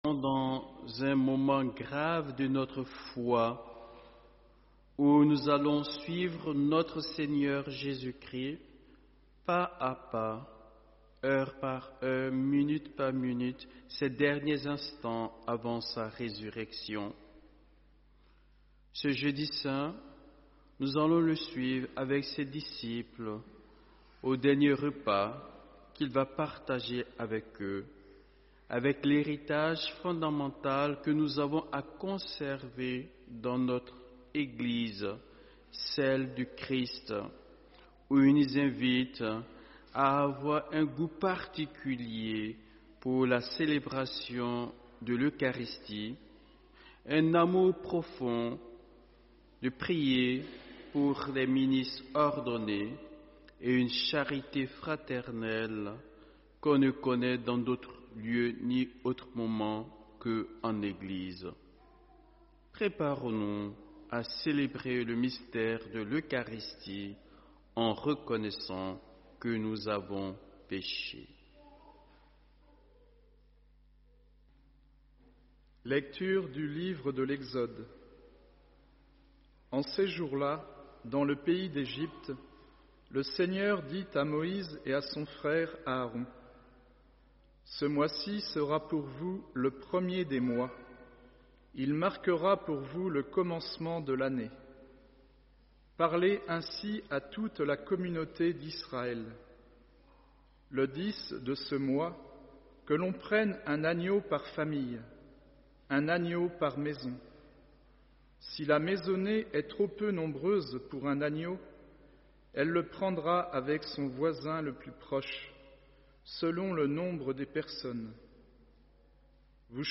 CÈNE DU SEIGNEUR jeudi-saint 17 avril 2025